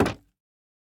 Minecraft Version Minecraft Version latest Latest Release | Latest Snapshot latest / assets / minecraft / sounds / block / bamboo_wood_trapdoor / toggle3.ogg Compare With Compare With Latest Release | Latest Snapshot